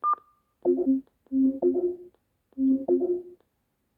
MRT-startup.wav